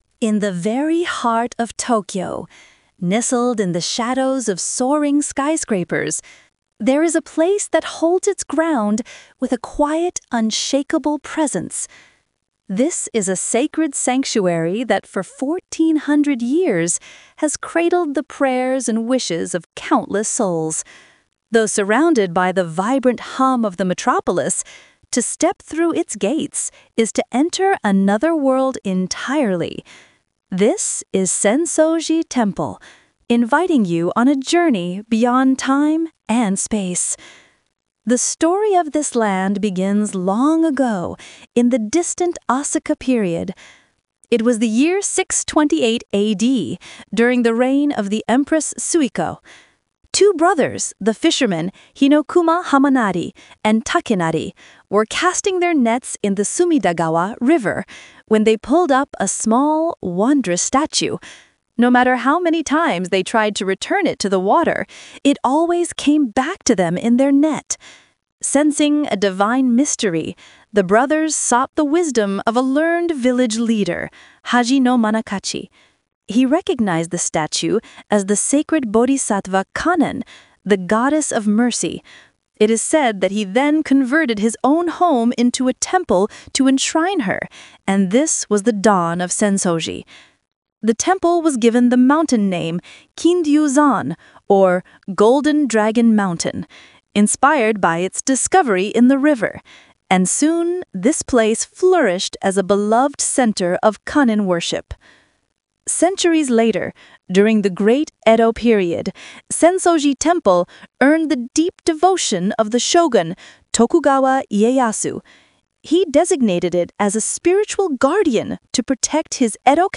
everyone's guide | Your Personal & Easy AI Audio Guide to Japan
We use cutting-edge voice technology to deliver narration so natural and expressive, it feels like a personal guide is speaking right beside you.